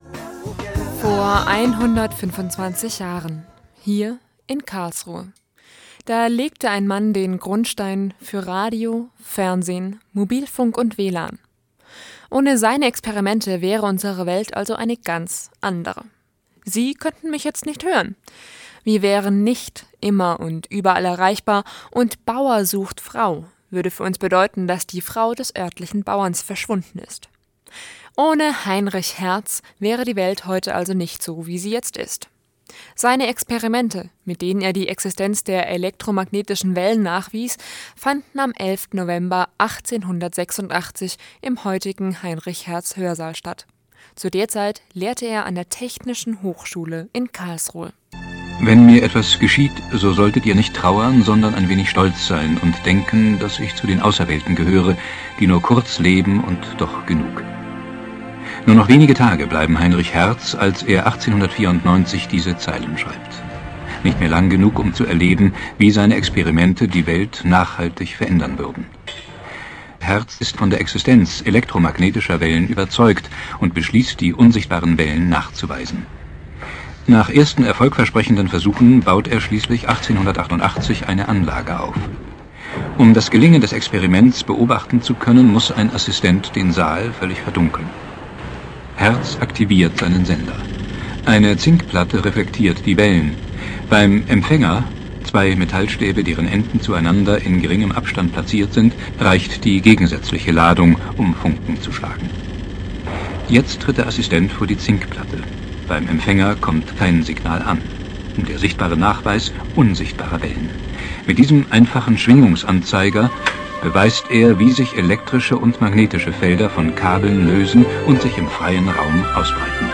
Beiträge rund ums KIT